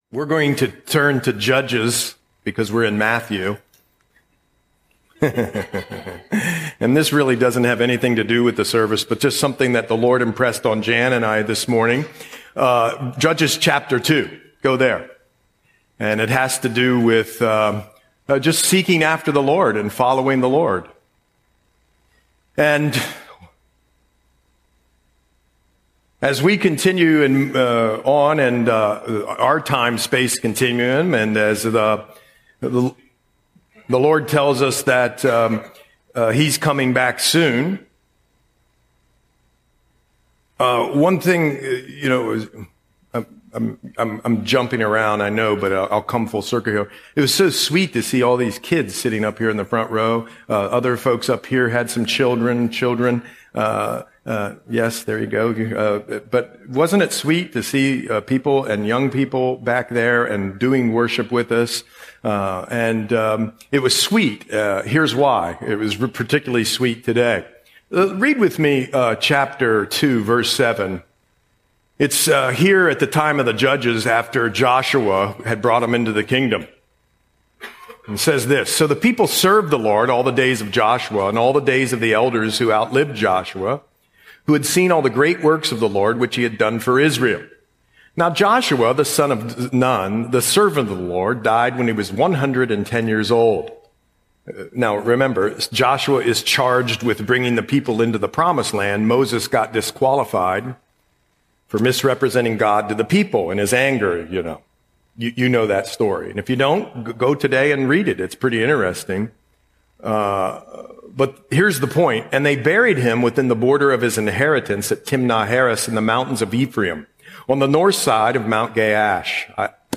Audio Sermon - September 28, 2025